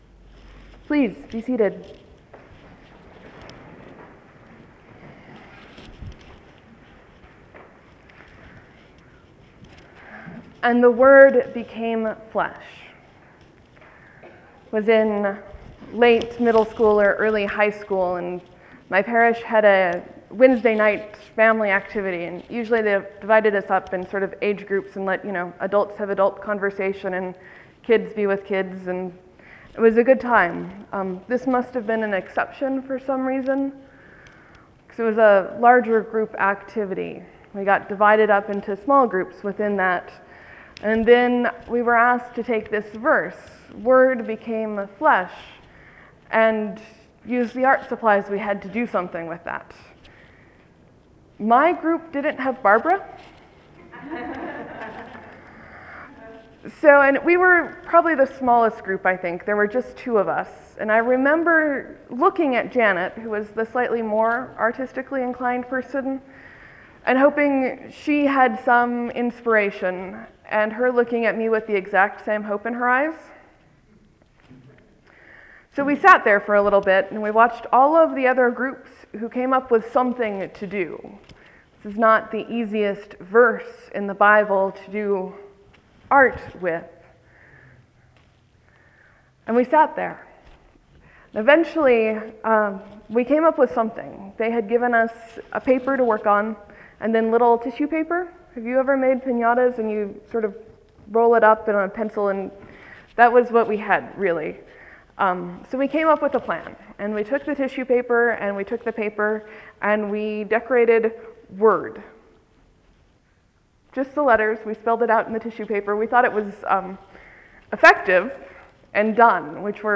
Christmas, Sermon, , , , , , 1 Comment